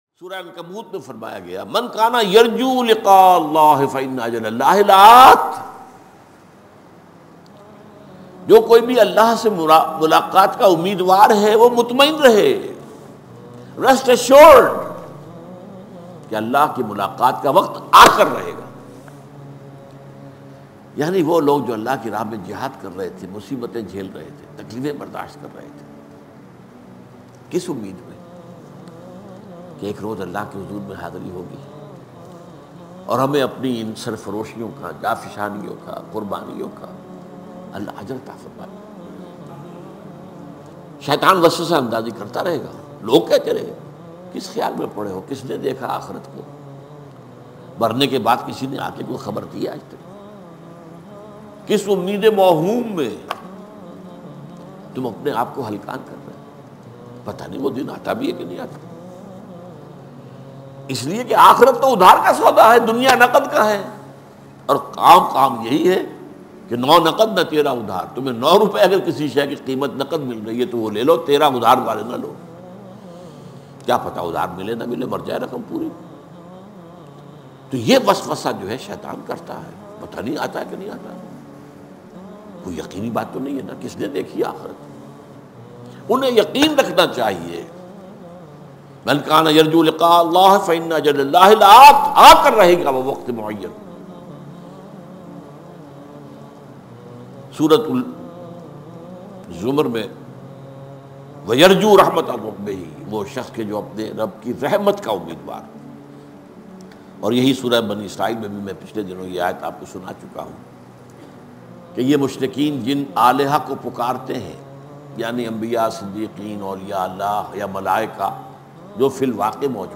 ALLAH Se Mulakat Heart Touching Bayan MP3 Download By Dr Israr Ahmed
Dr Israr Ahmed R.A a renowned Islamic scholar.